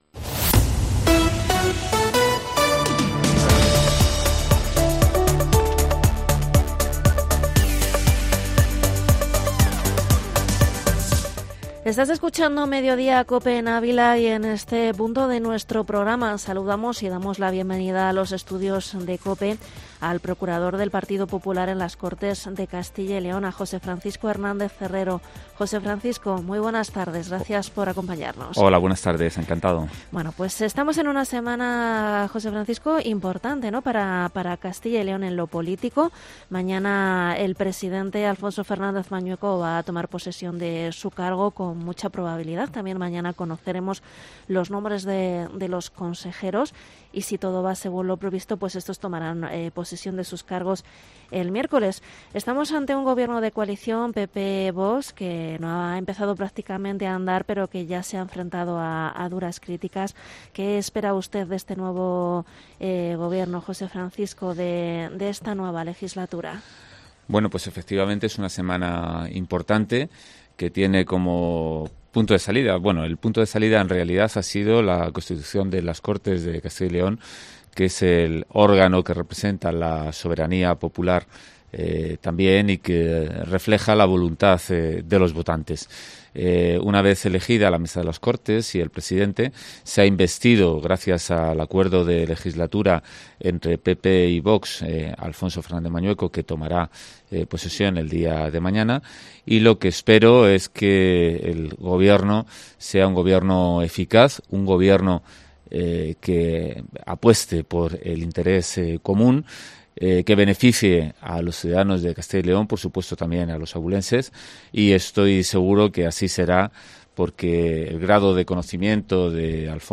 Entrevista procurador PP, José Francisco Hernández Herrero